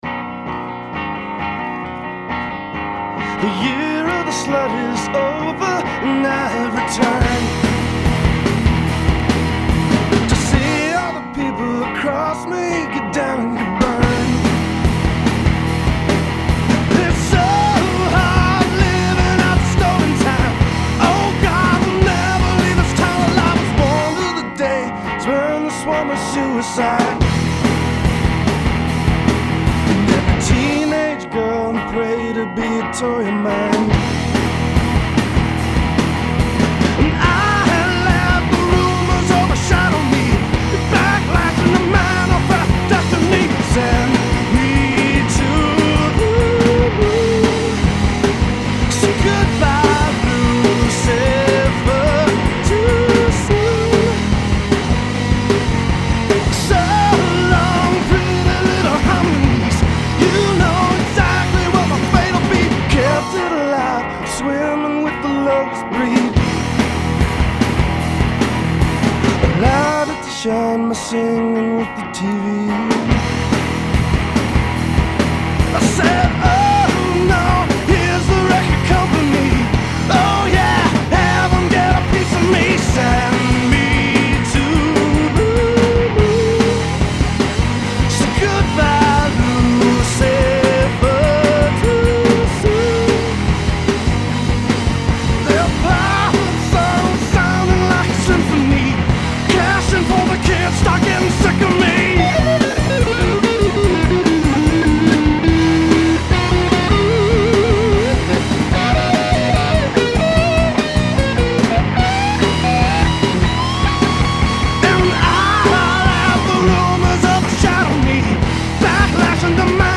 I still get them just listening to unmixed demo.